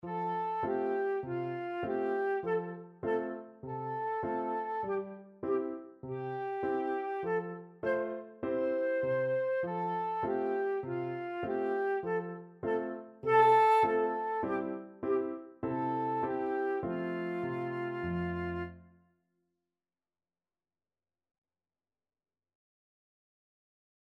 4/4 (View more 4/4 Music)
Allegretto
Traditional (View more Traditional Flute Music)